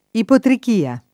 ipotrichia [ ipotrik & a ] s. f. (med.)